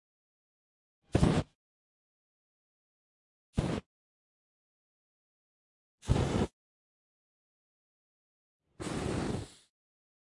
除臭火焰喷射器。